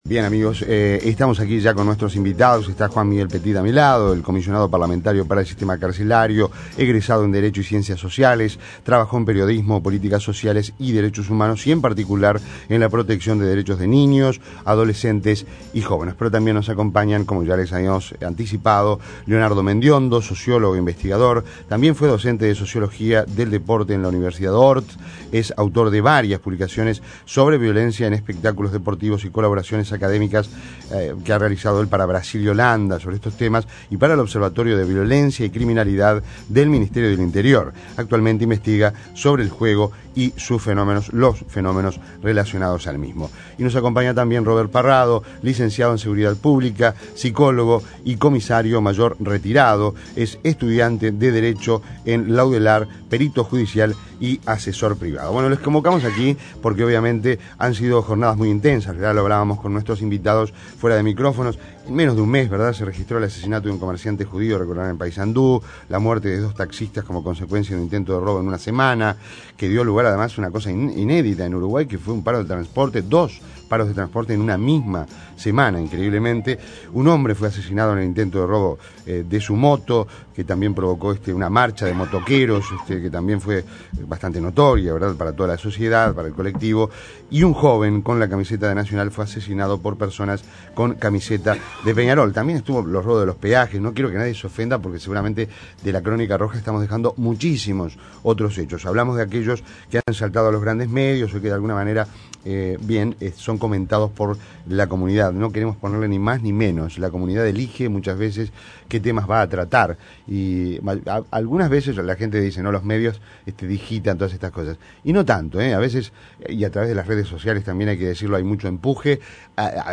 Mesa sobre la situación de violencia